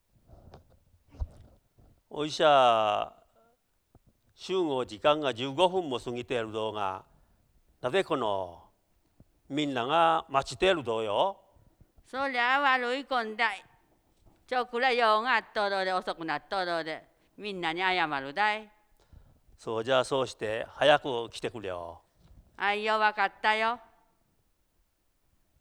早川町のことば：雨畑・茂倉・奈良田の音声資料集|いずこに
会話（ロールプレイ） ─奈良田─